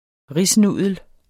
Udtale [ ˈʁis- ]